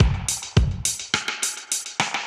Index of /musicradar/dub-designer-samples/105bpm/Beats
DD_BeatA_105-03.wav